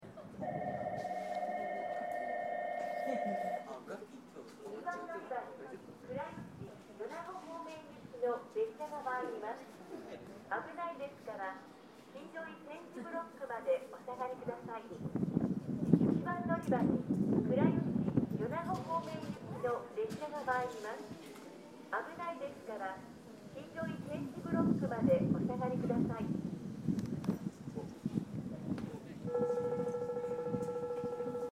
この駅では接近放送が設置されています。
接近放送普通　米子行き接近放送です。